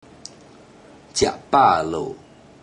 Click each Romanised Teochew word or phrase to listen to how the Teochew word or phrase is pronounced.
Ziad10 pa4 lou0.
Ziad10pa4lou0.mp3